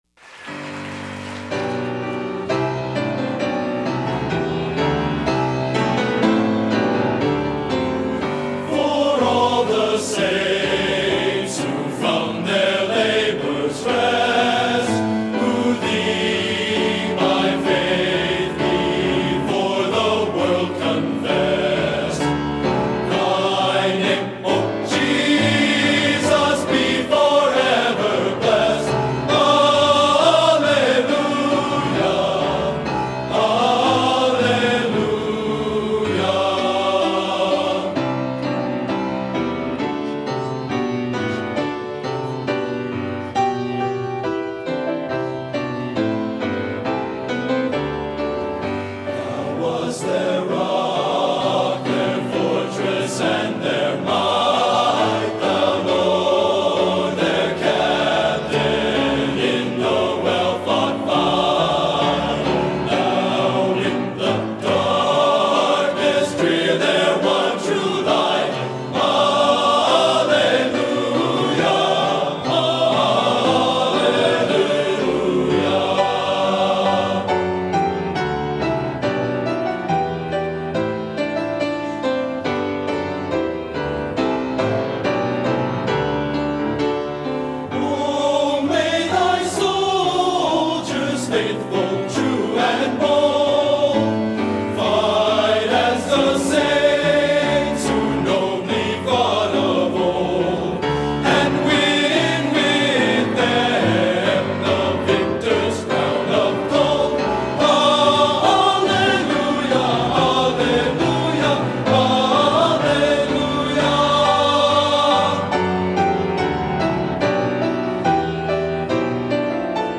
Genre: Sacred | Type: